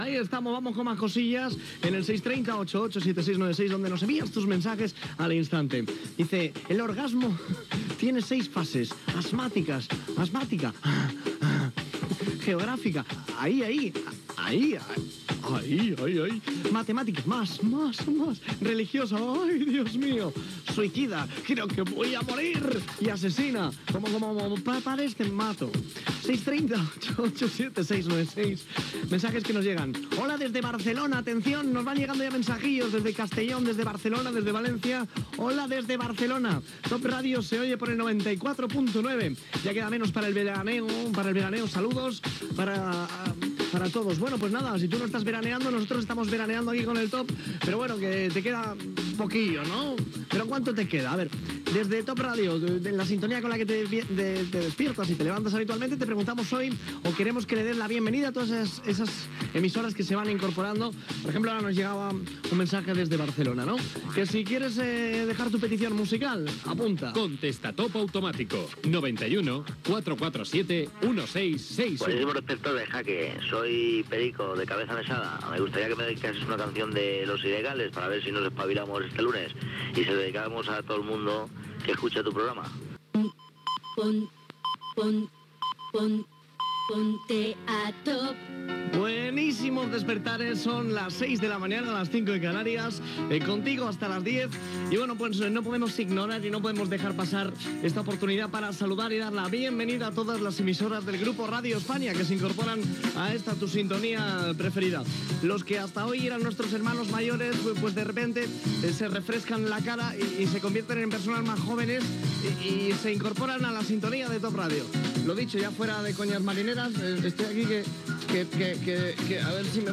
Fases de l'orgasme, esment a un missatge de Barcelona i a l'ampliació de freqüències de la cadena, contestador automàtic, hora i benvinguda a les noves emissores de Radio España que ara formen part de Top Radio, tema musical
Musical
FM